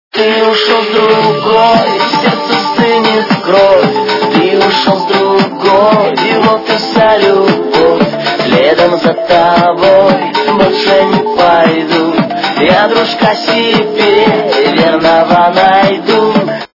украинская эстрада
качество понижено и присутствуют гудки